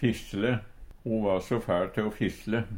DIALEKTORD PÅ NORMERT NORSK fisjle sladre Infinitiv Presens Preteritum Perfektum å fisjle fisjla fisjla fisjle Eksempel på bruk Ho va so fæL te o fisjle Hør på dette ordet Ordklasse: Verb Attende til søk